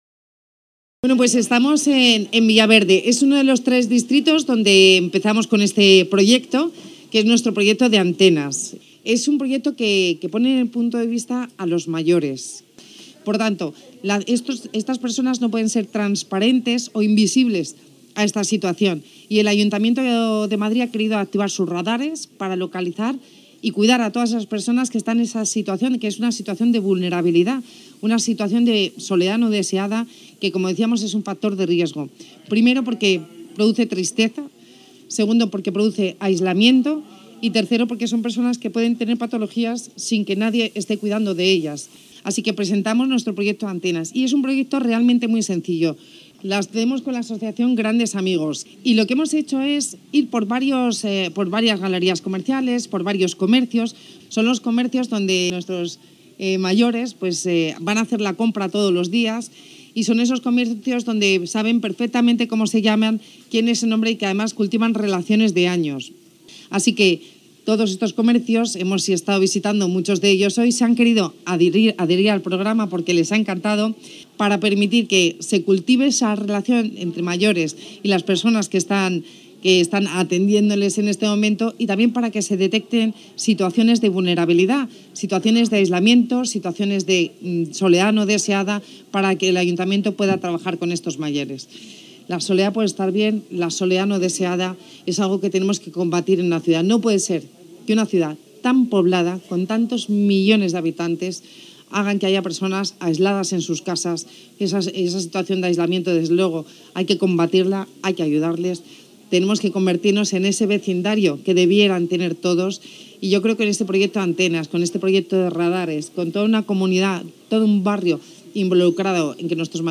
La vicealcaldesa de Madrid, Begoña Villacís, ha presentado este viernes el programa ‘Madrid Vecina’, desarrollado por el Ayuntamiento y la ONG Grandes Amigos para prevenir y detectar la soledad no deseada en las personas mayores de la ciudad desde el enfoque comunitario.